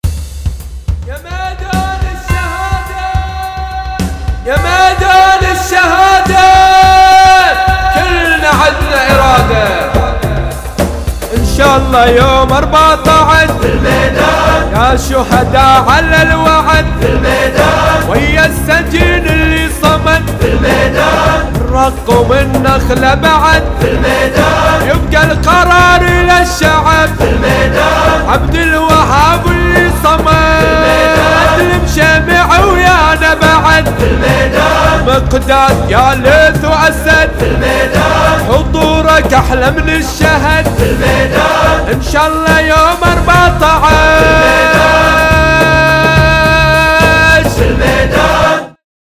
أناشيد بحرينية نشيد